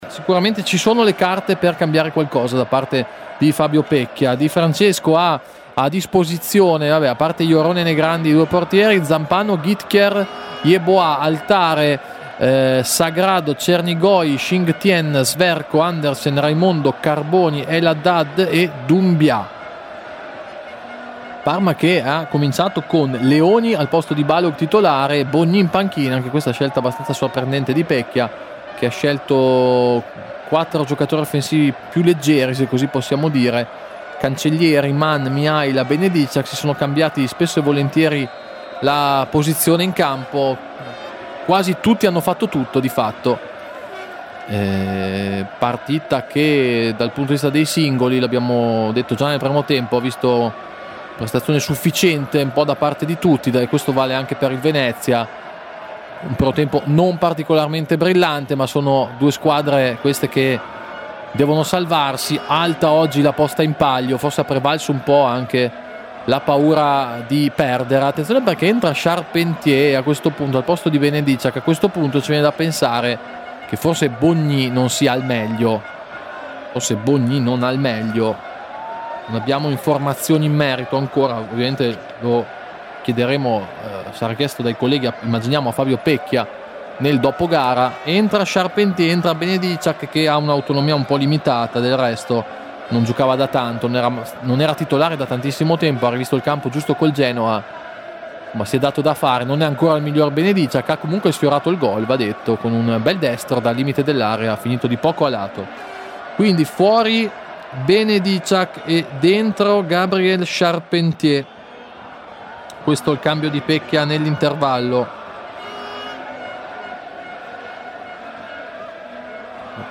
Radiocronache Parma Calcio Venezia - Parma 2° tempo - 9 novembre 2024 Nov 09 2024 | 00:51:53 1x 00:00 / 00:51:53 Subscribe Share RSS Feed Share Link Embed